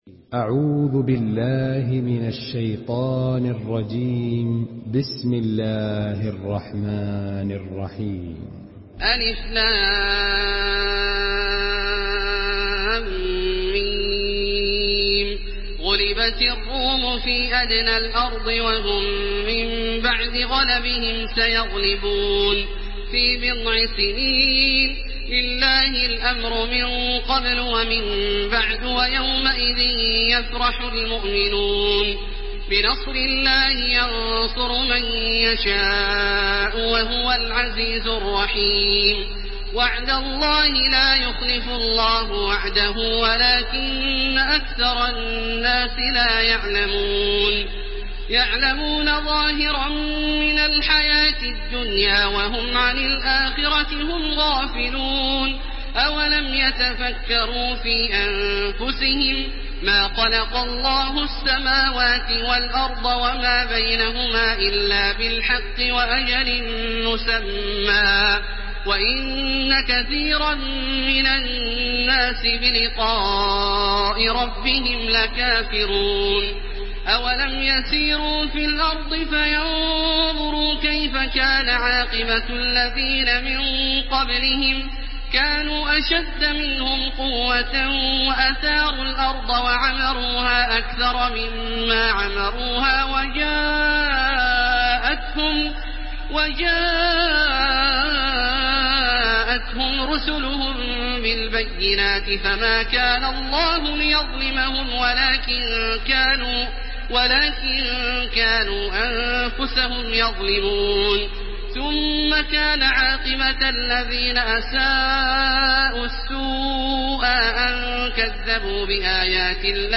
Surah আর-রূম MP3 in the Voice of Makkah Taraweeh 1430 in Hafs Narration
Surah আর-রূম MP3 by Makkah Taraweeh 1430 in Hafs An Asim narration.